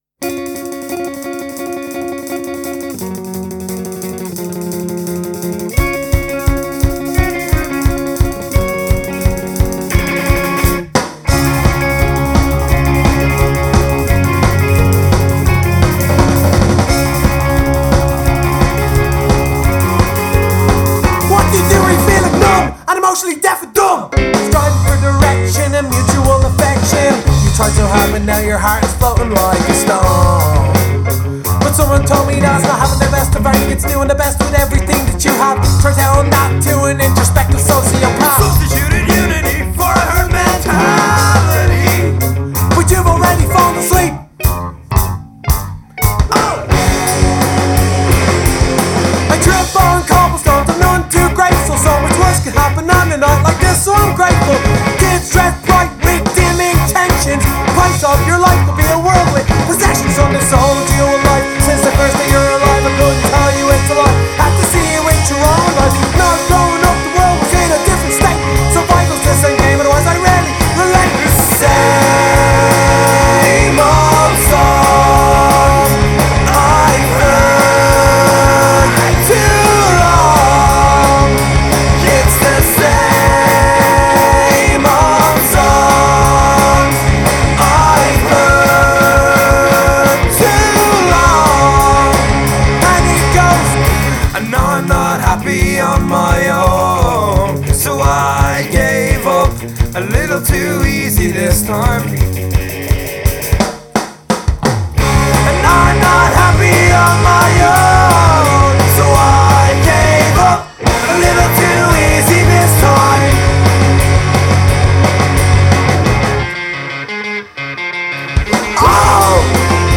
Bass/Vocals/Mouth Organ/Double-Bass
Guitar/Vocals
Drums
Keys